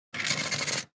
Welding Noise Single Shot
SFX
yt_9sFts-cgrgA_welding_noise_single_shot.mp3